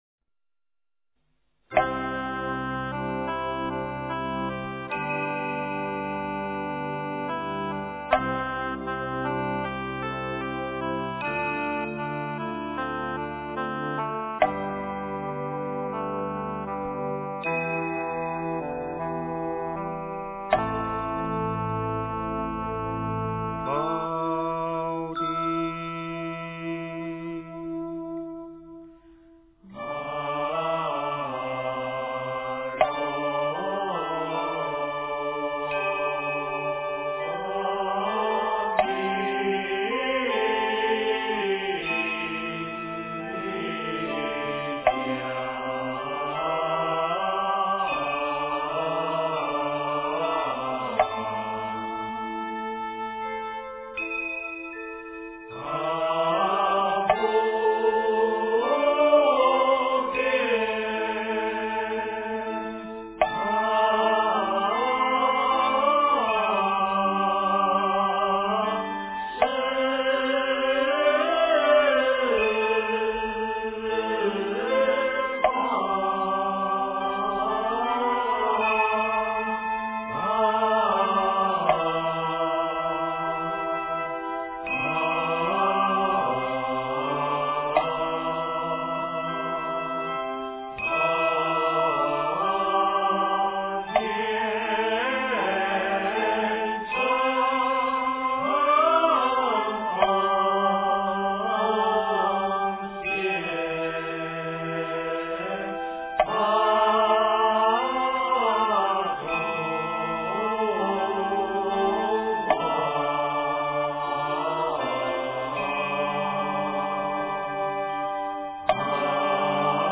宝鼎祝福香赞--如是我闻 经忏 宝鼎祝福香赞--如是我闻 点我： 标签: 佛音 经忏 佛教音乐 返回列表 上一篇： 蒙山施食--天宁寺 下一篇： 佛说阿弥陀经--僧团 相关文章 赞佛偈绕佛--上江城梵呗 赞佛偈绕佛--上江城梵呗...